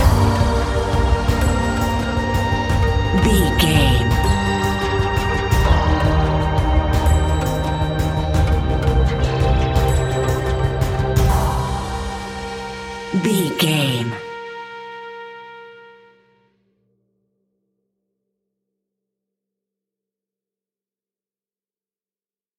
Aeolian/Minor
scary
ominous
eerie
synthesizer
drum machine
ticking
electronic music
Horror Synths